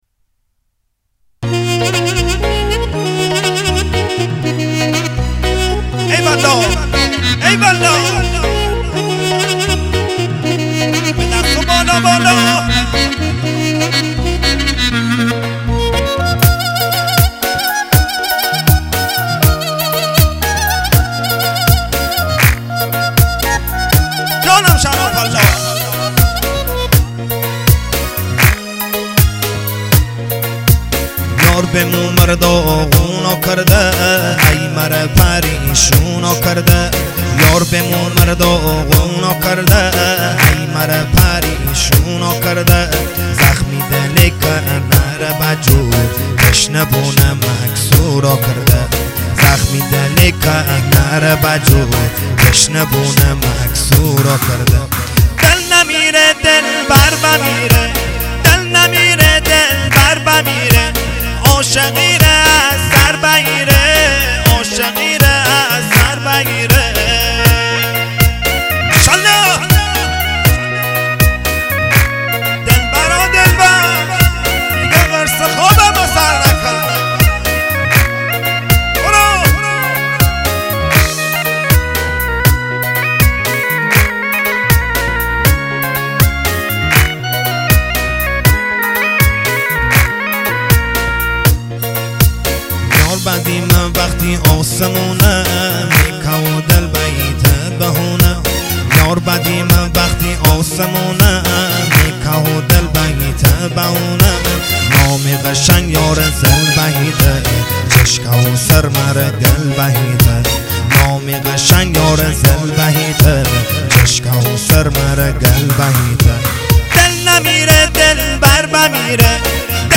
آهنگ مازندرانی دلبر